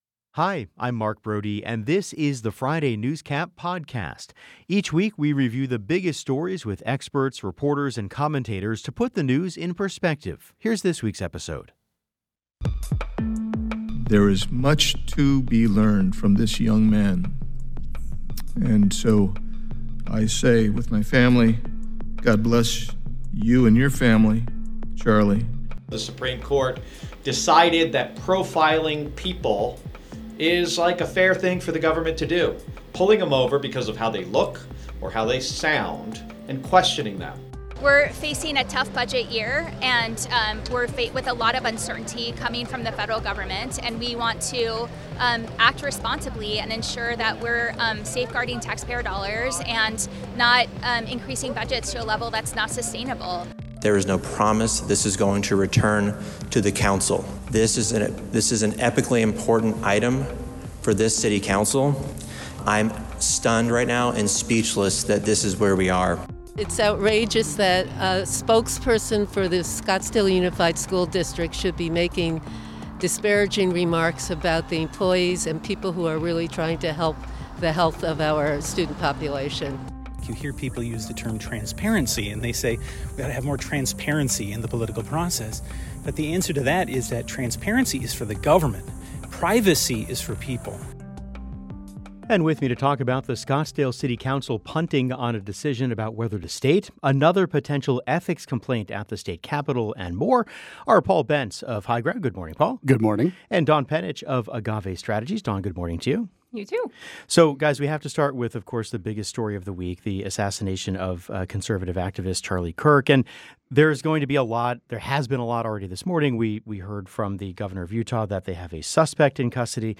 The Show's Friday NewsCap is a weekly review of the biggest stories with experts, reporters and commentators to put the news in perspective.